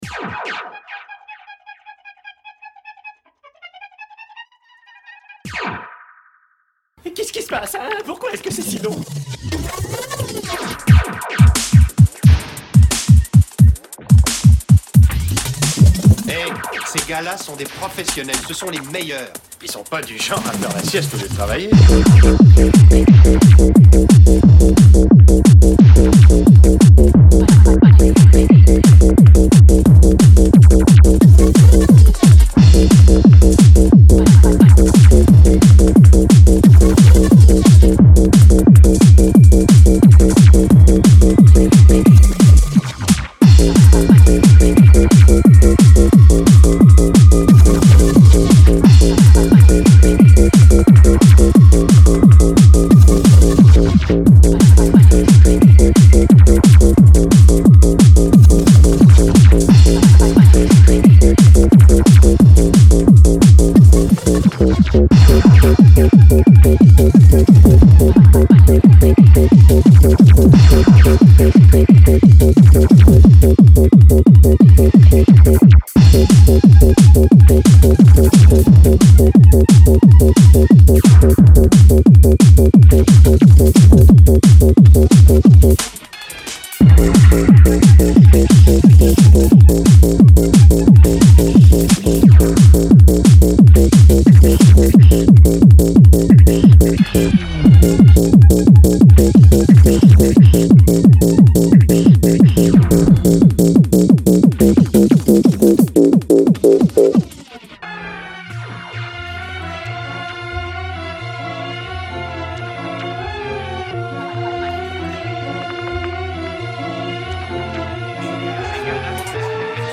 Etiquetes: Hardtek